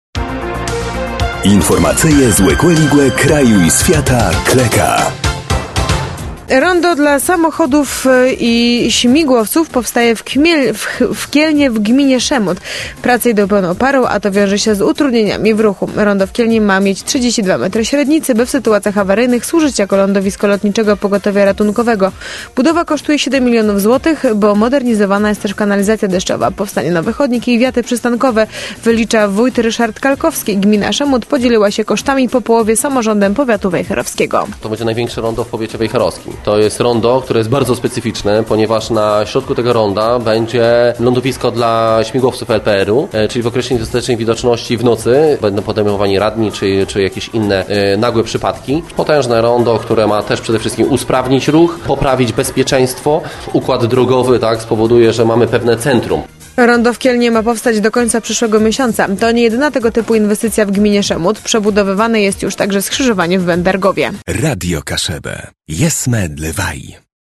– Budowa kosztuje 7 mln zł, bo modernizowana jest też kanalizacja deszczowa, powstanie nowy chodnik i wiaty przystankowe – wylicza wójt Ryszard Kalkowski.